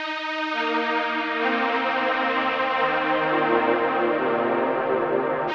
Orc Strings 04.wav